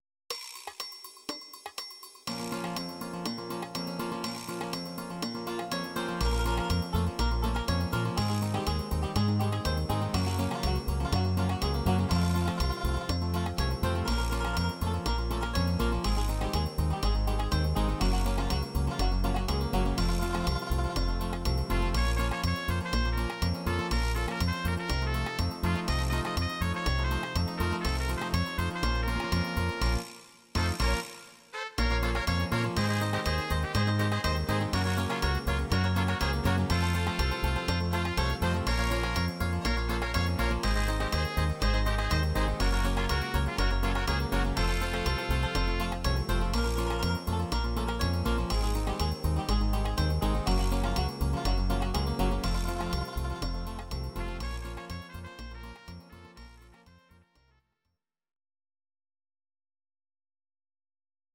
Audio Recordings based on Midi-files
Ital/French/Span, Instrumental